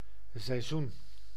Ääntäminen
Synonyymit jaargetijde jaargetij Ääntäminen : IPA: [sɛj.zun] Tuntematon aksentti: IPA: /sɛi̯ˈzun/ Haettu sana löytyi näillä lähdekielillä: hollanti Käännös Ääninäyte Substantiivit 1. season US Suku: n .